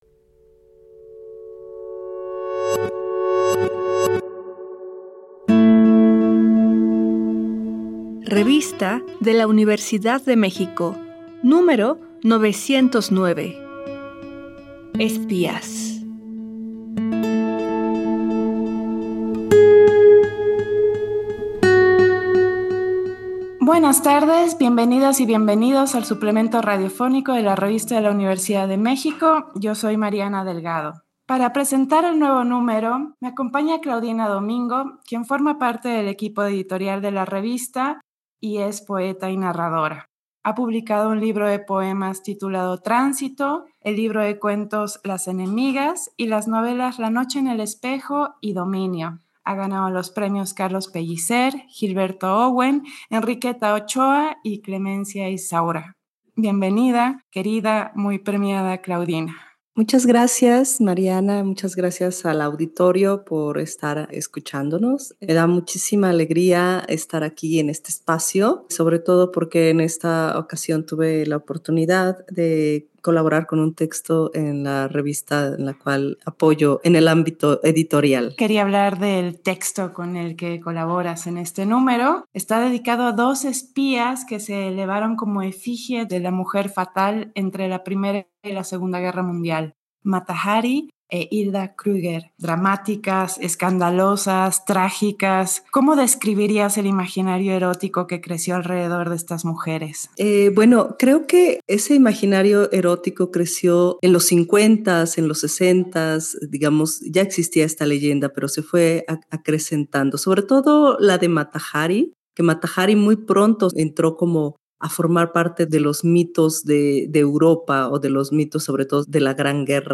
Fue transmitido el jueves 6 de junio de 2024 por el 96.1 FM.